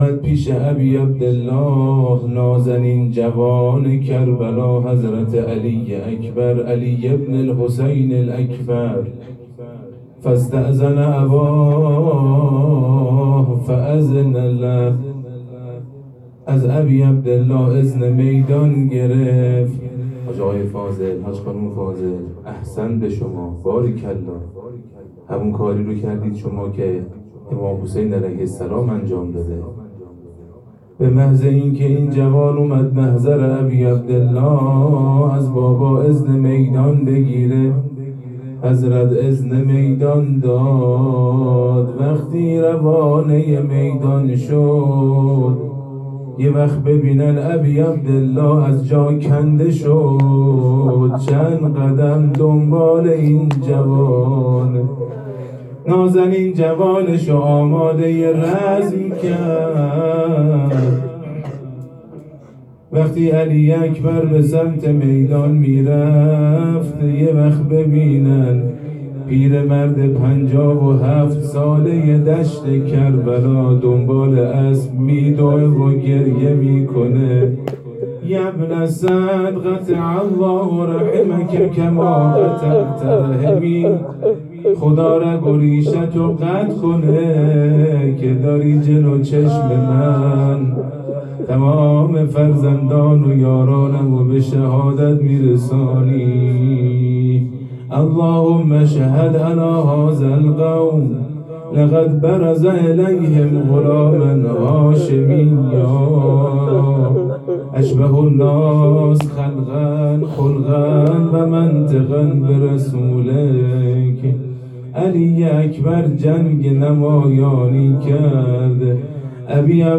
خیمه گاه - شجره طیبه صالحین - روضه حجت الاسلام